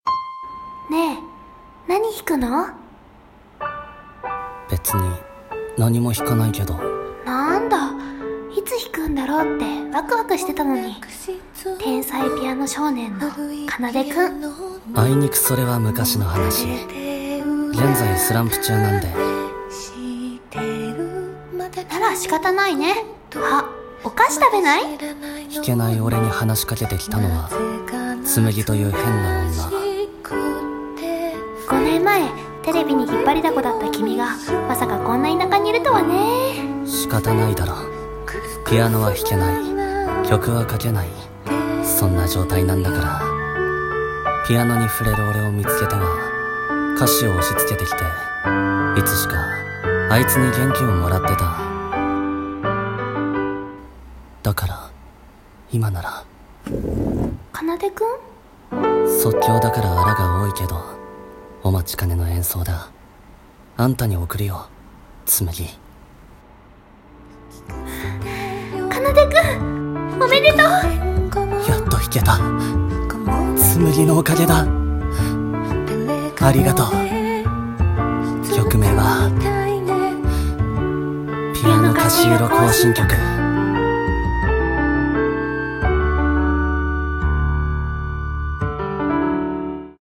【声劇】ピアノ菓子色幸心曲